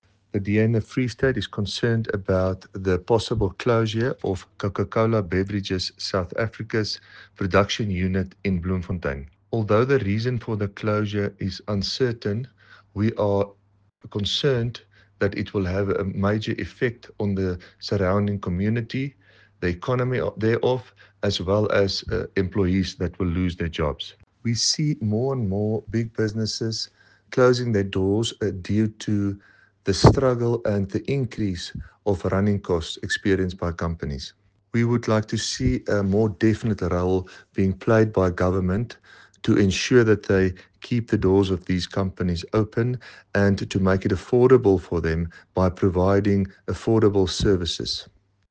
English and Afrikaans soundbites by David van Vuuren MPL and